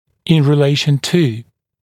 [ɪn rɪ’leɪʃn tuː][ин ри’лэйшн ту:]по отношению к (в т.ч. при пространственном анализе)